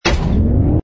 engine_oe_h_fighter_start.wav